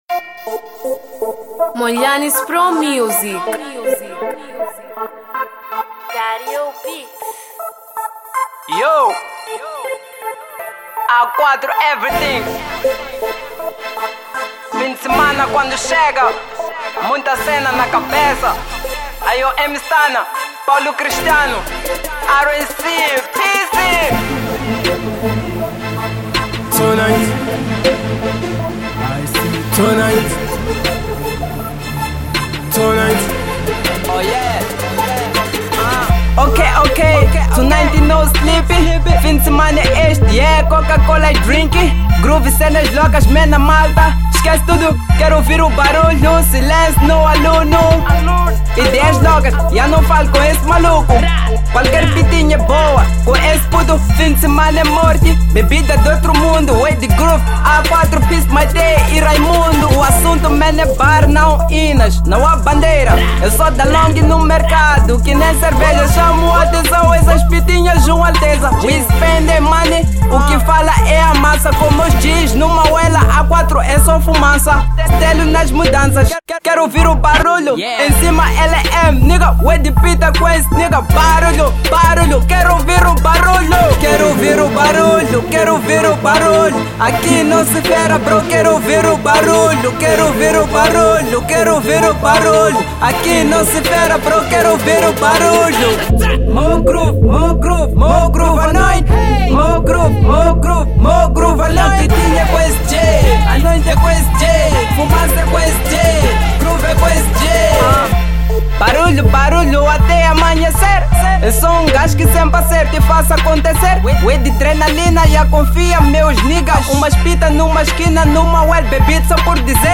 Mp3 Género: Trap Tamanho